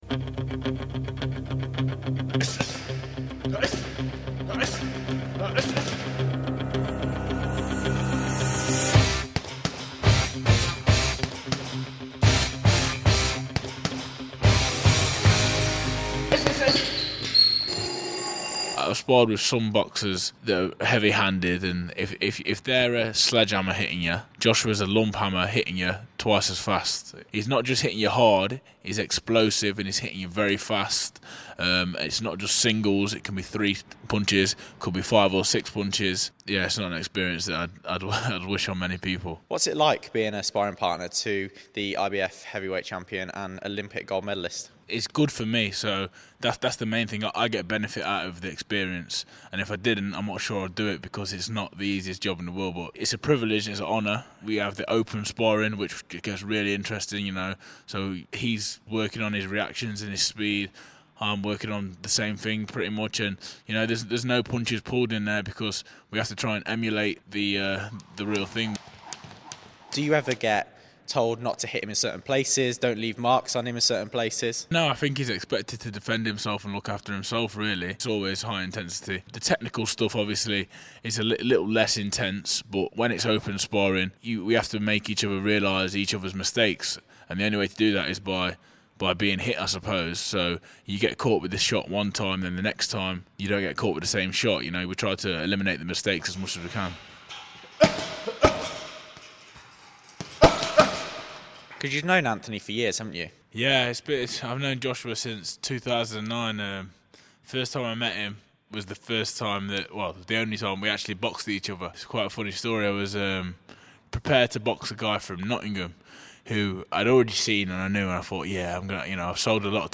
Burton Boxer Frazer Clarke is currently putting IBF Heavyweight boxing champion Anthony Joshua through his paces ahead of his fight against Wladimir Klitschko at Wembley Stadium in April 2017. I caught up with him for BBC Radio Derby back in Burton where he started boxing as a child.